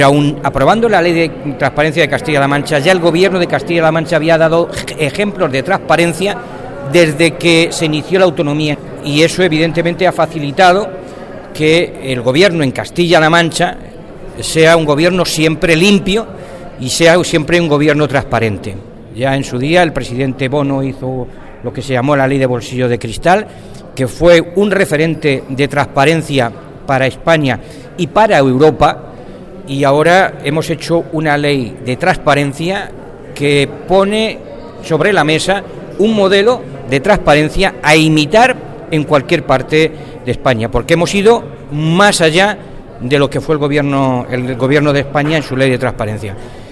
El viceconsejero de Administración Local y Coordinación Administrativa, Fernando Mora, habla del carácter pionero y ejemplarizante de Castilla-La Mancha en materia de transparencia.